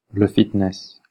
Ääntäminen
Ääntäminen US : IPA : [ˈfɪt.nɪs]